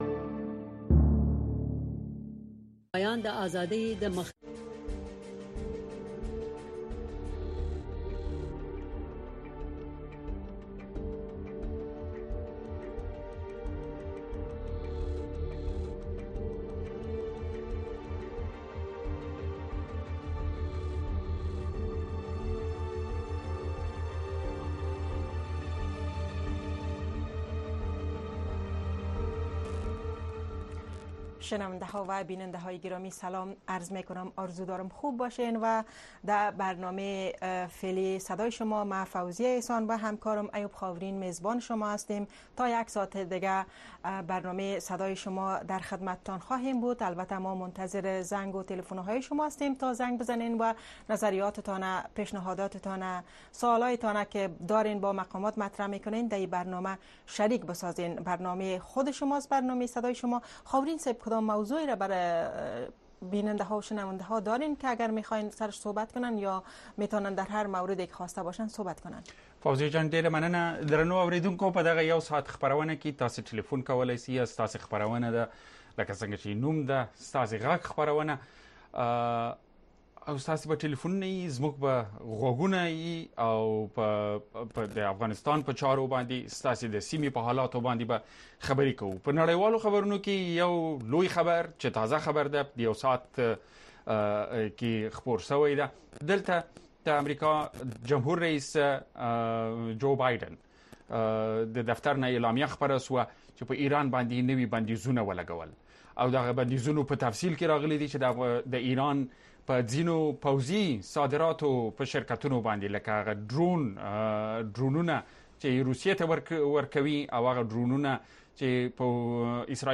این برنامه به گونۀ زنده از ساعت ۹:۳۰ تا ۱۰:۳۰ شب به وقت افغانستان نشر می‌شود.